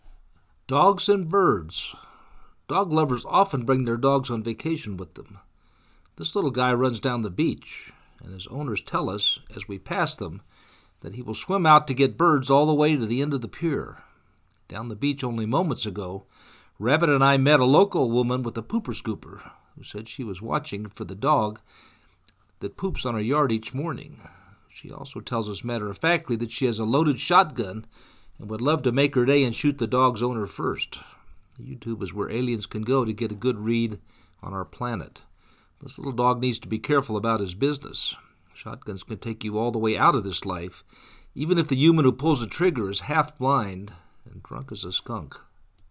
Dogs and Birds-Belize Morning walk
dogs-and-birds-3.mp3